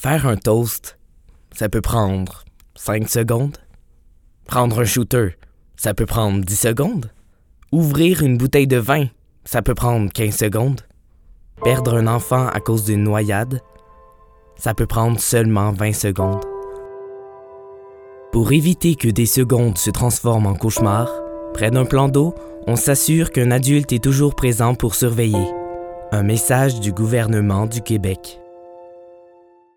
Démo pub gouvernementale